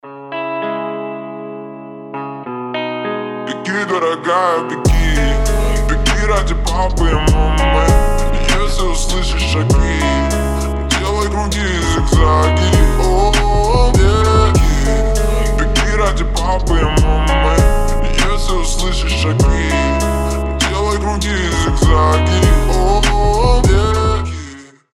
гитара
атмосферные
басы
медленные
Chill Trap
ремиксы